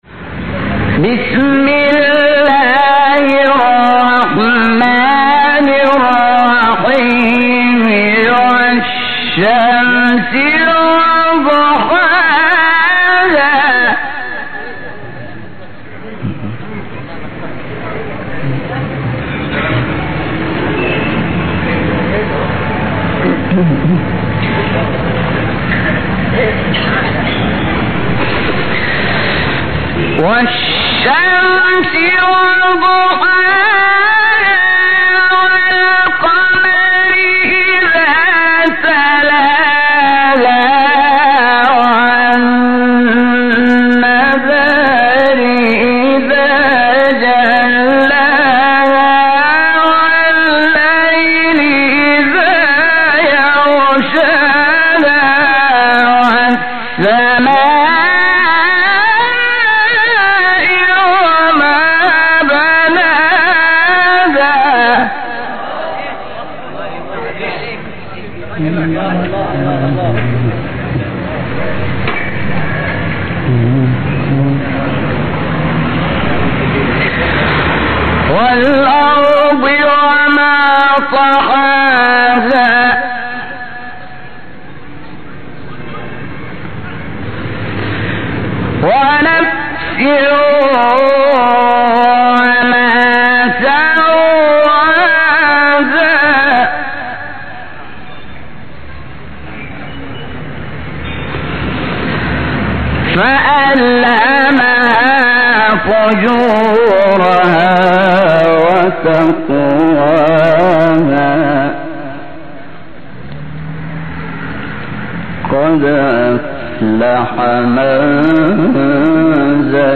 تلاوت بسیار زیبای سوره شمس استاد شات انور | نغمات قرآن | دانلود تلاوت قرآن